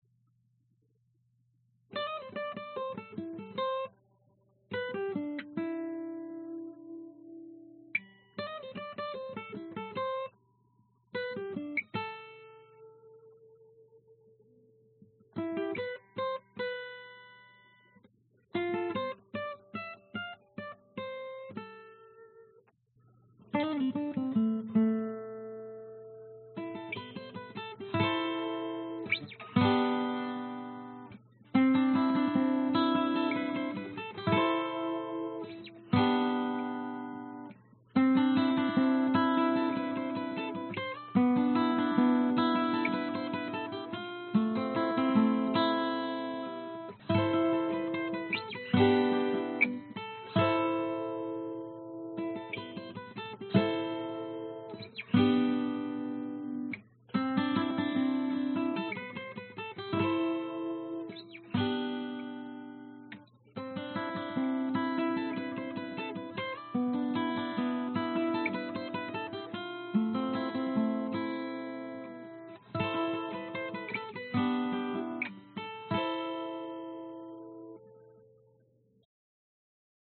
单声道干式原声吉他 Riff
Tag: 原声吉他 原声吉他曲